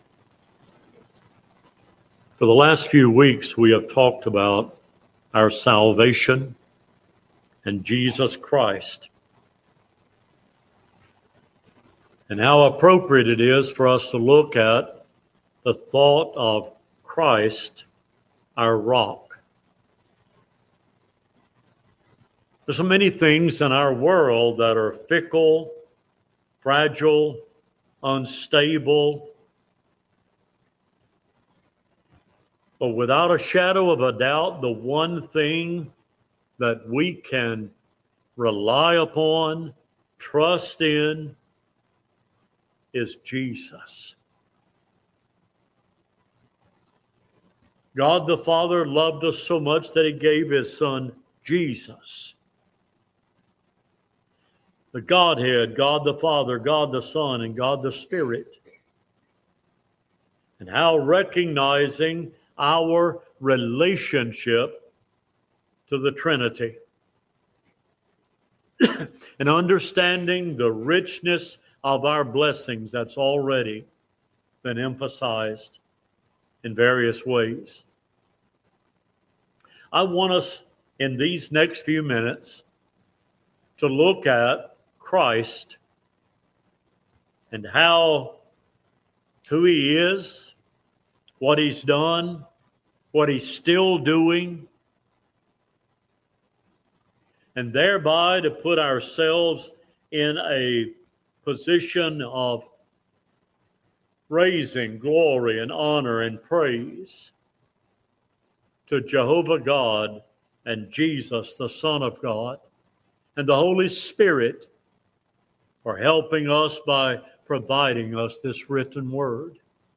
Cedar Grove Church of Christ July 20 2025 AM Sunday Sermon - Cedar Grove Church of Christ